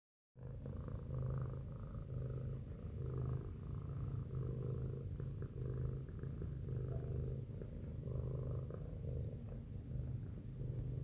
Mijn kat is zó hard aan het spinnen